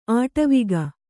♪ āṭaviga